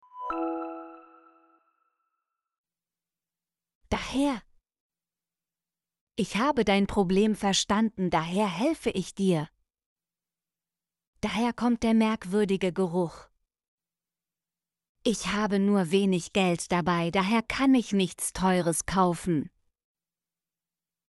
daher - Example Sentences & Pronunciation, German Frequency List